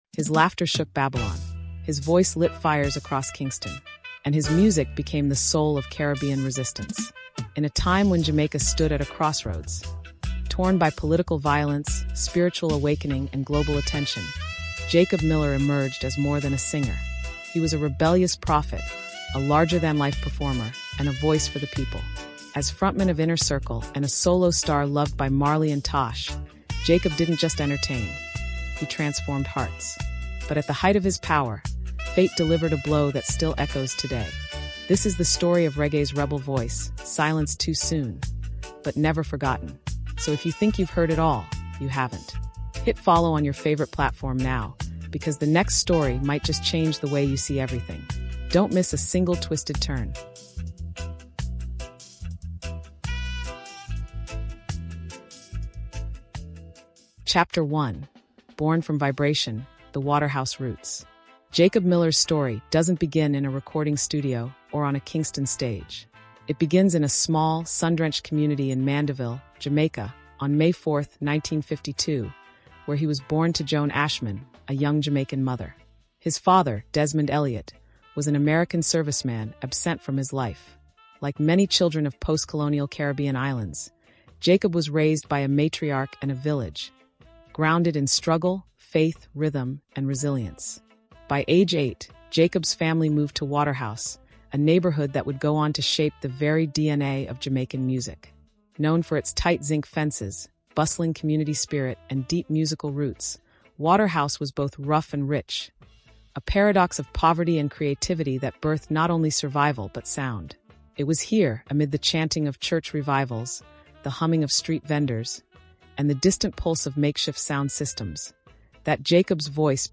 With exclusive interviews, deep-dive historical analysis, and rare archival recordings, we explore the powerful intersection of Caribbean identity, dancehall evolution, and Afro-Caribbean resistance.